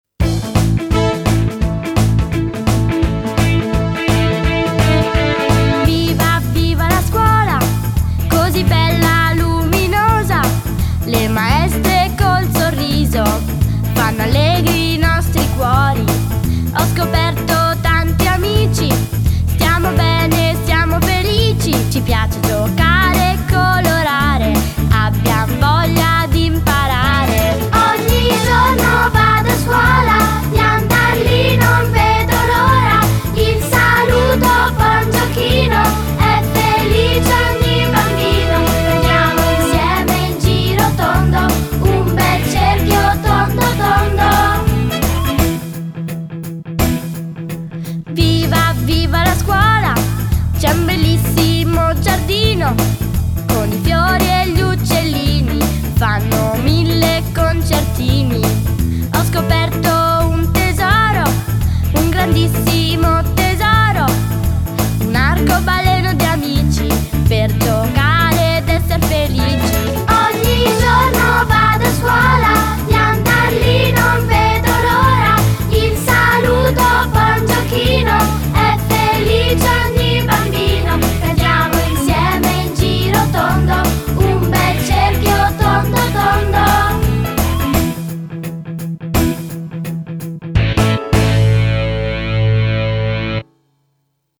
CANZONCINA: Viva viva la scuola